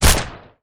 etfx_shoot_pistol03.wav